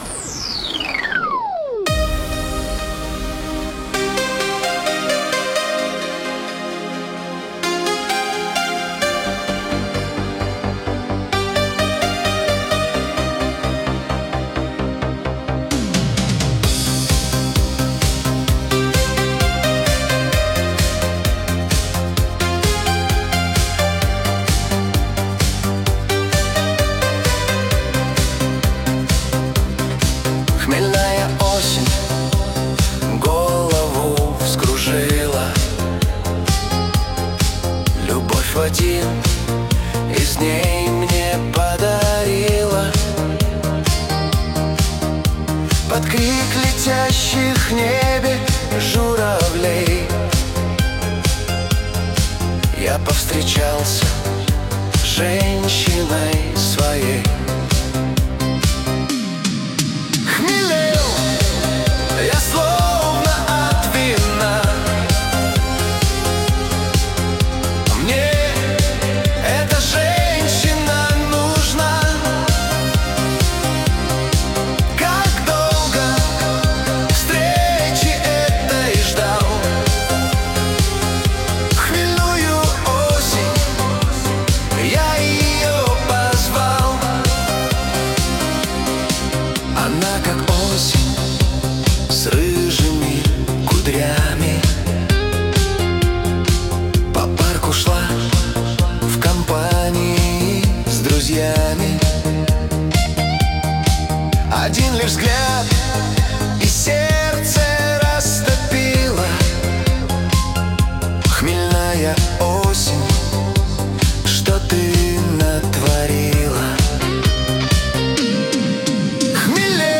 Качество: 320 kbps, stereo
Нейросеть Песни 2025, Стихи